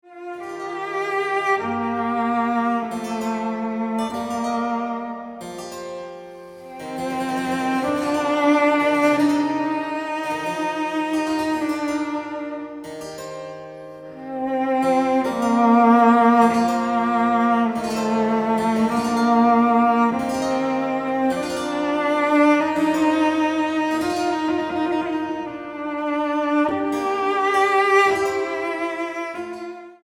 violoncelo